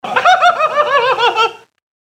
短い男性の笑い声